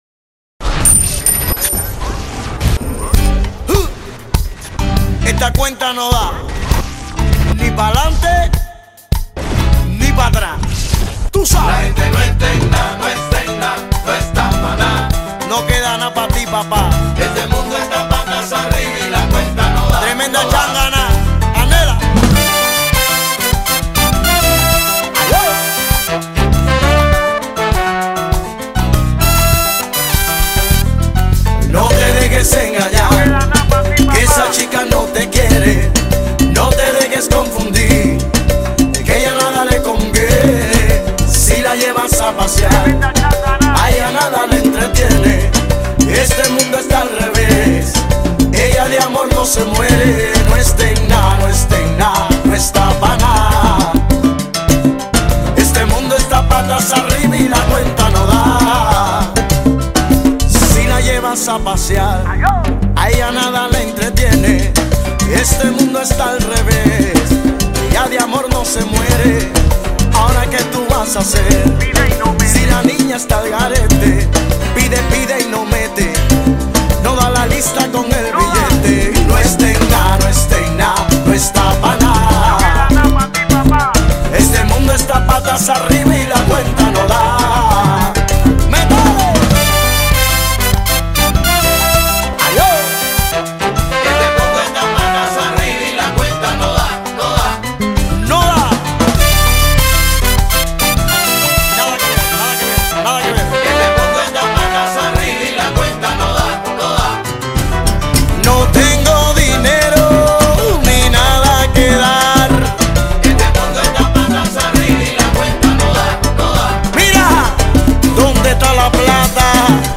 ¡SALSA INTERNACIONAL! ✈